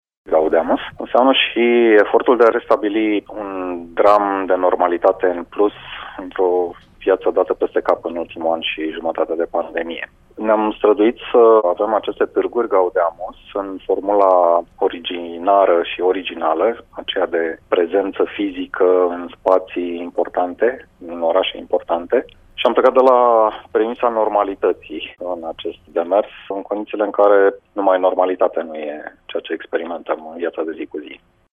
Director general interimar, al Societăţii Române de Radiodifuziune, Liviu Popescu: